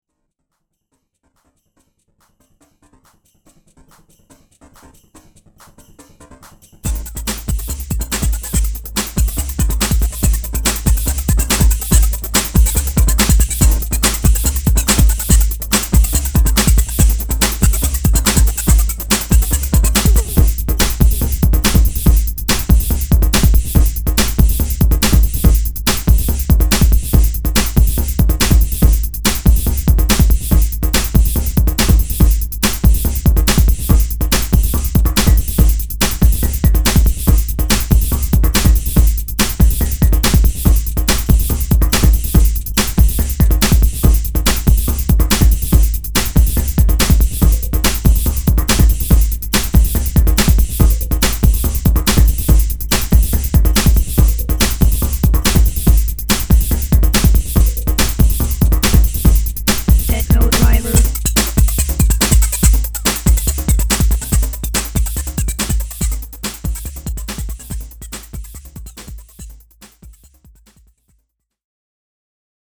DETROIT
two additional fast paced Electrofunk tracks